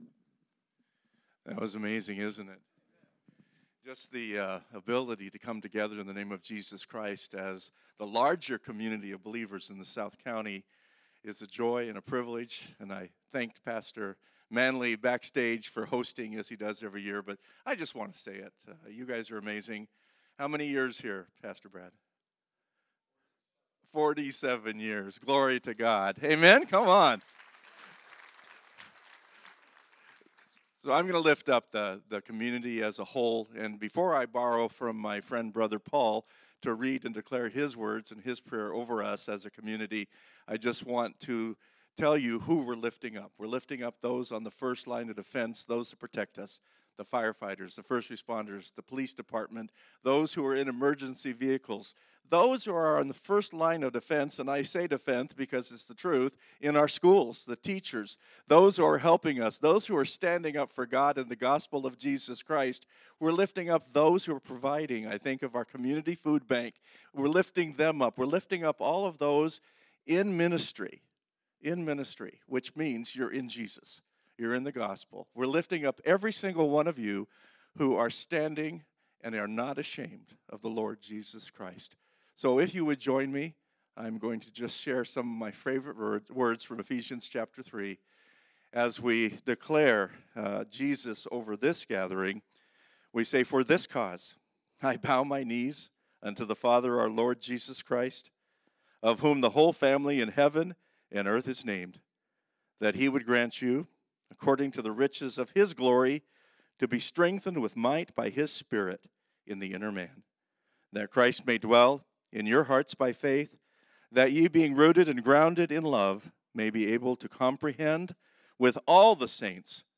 Series: Good Friday Type: Sermons